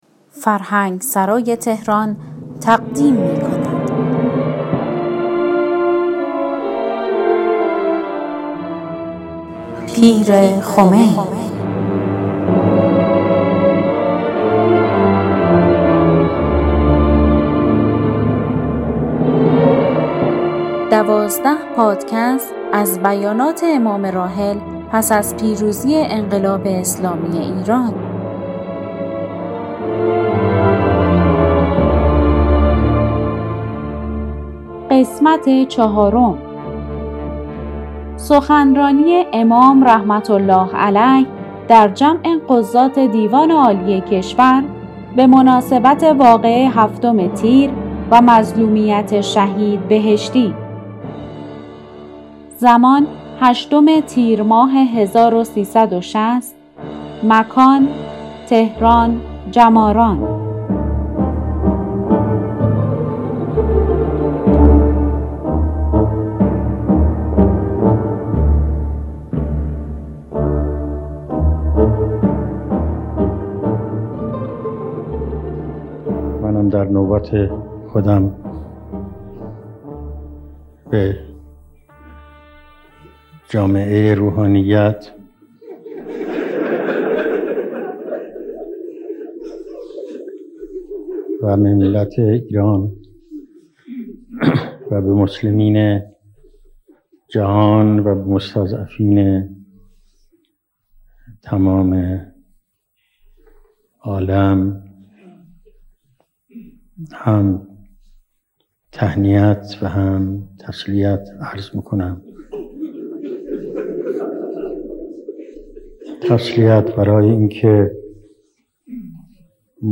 در ادامه سخنان امام (ره) را در جمع قضات دیوان عالی کشور پیرامون مظلومیت شهید بهشتی که در تیرماه سال 1360 در تهران ایراد کردند، می‌شنویم.